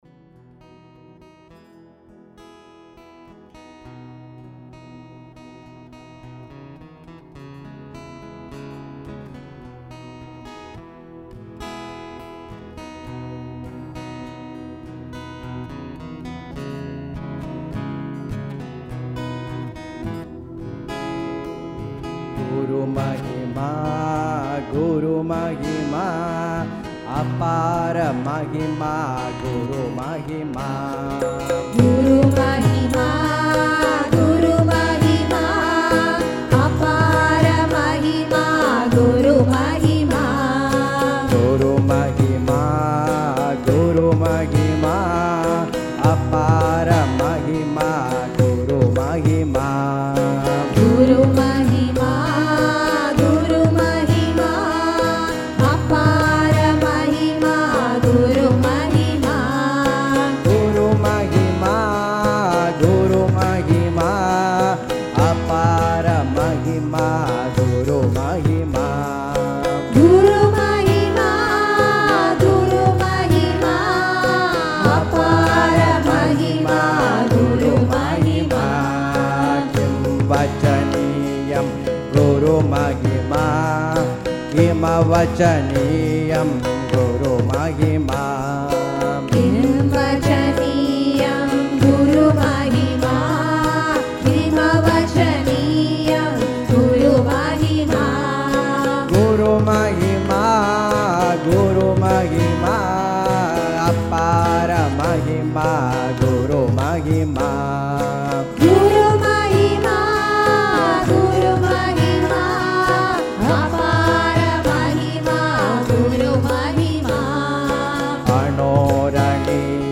Audio Recording of the 2019 Sri Ribhu Rishinatha day celebration at the SAT Temple
devotional songs by devotees